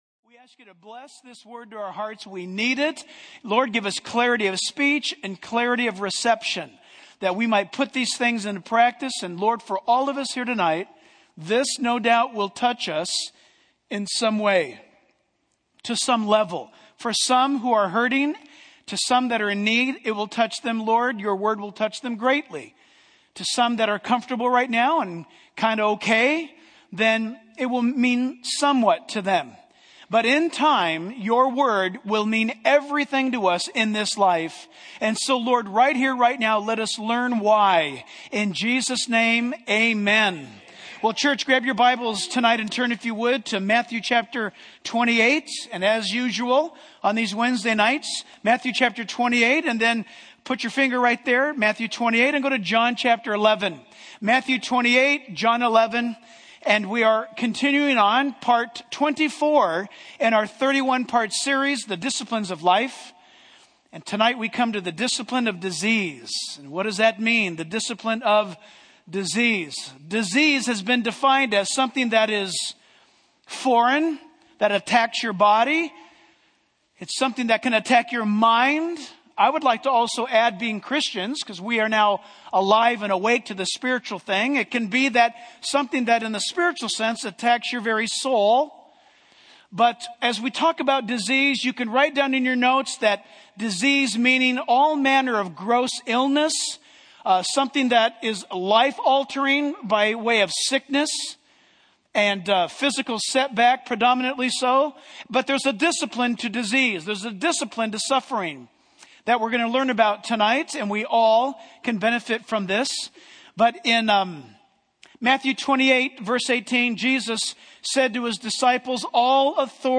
In this sermon, the speaker reflects on the training of Navy SEALs and the bond that is formed between partners through intense and life-threatening experiences. He draws a parallel between this bond and the relationship between believers and God. The speaker emphasizes that while life in this broken world is subject to suffering, there is hope in God's plan.